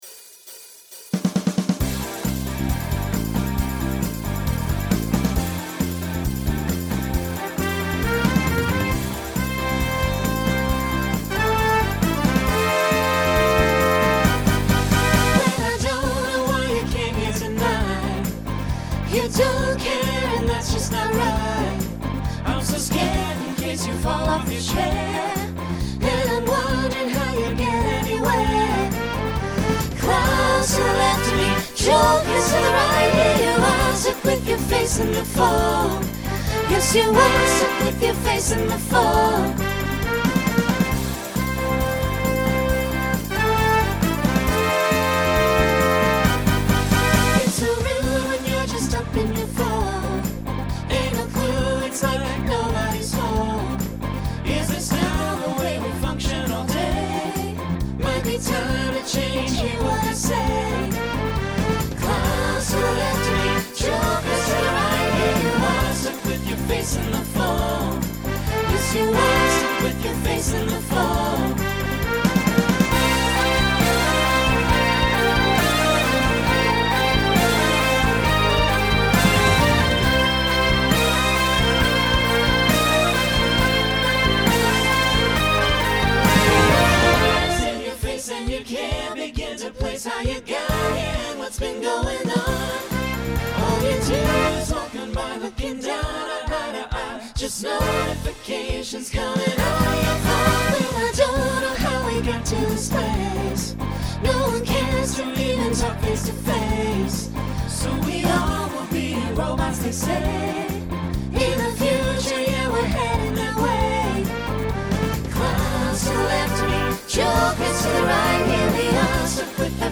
Genre Rock Instrumental combo
Novelty Voicing SATB